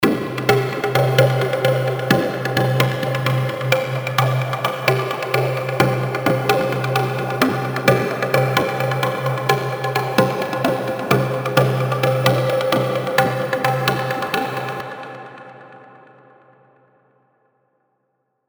Download Percussion sound effect for free.
Percussion